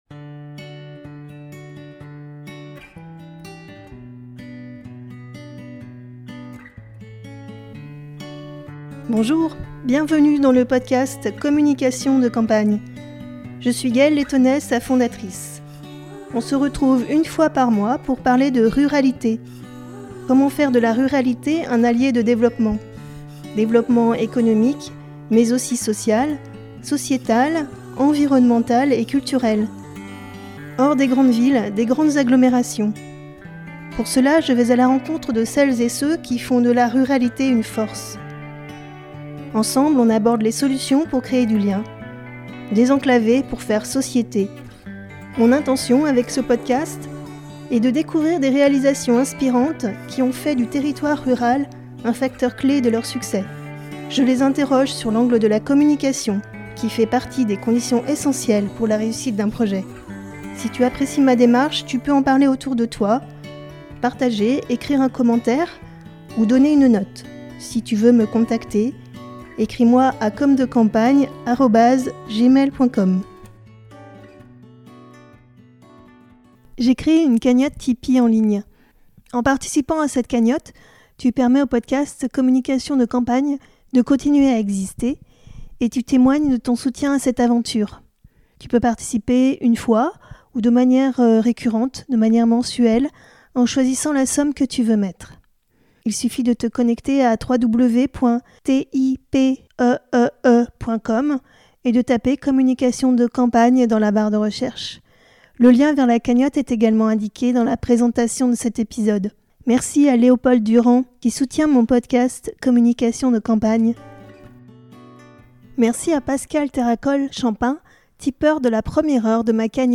Communication de campagne Interview